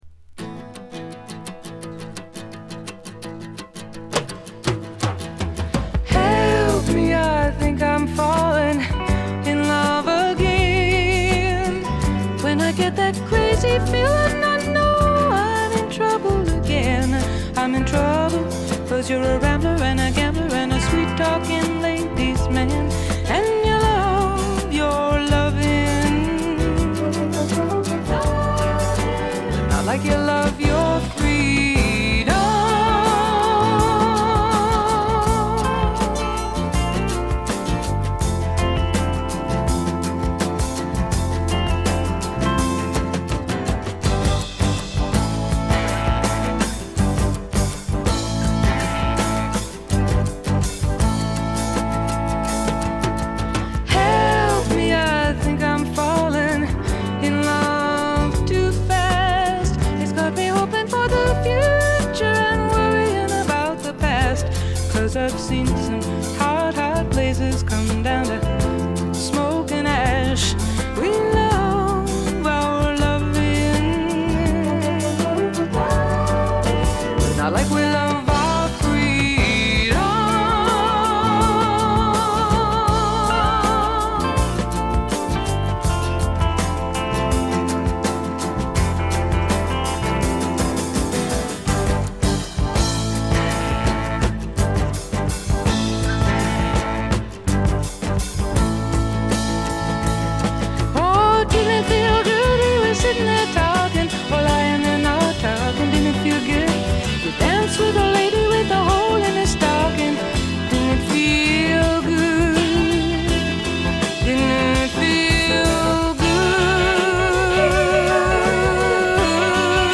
*** LP ： USA 74
ほとんどノイズ感無し。
試聴曲は現品からの取り込み音源です。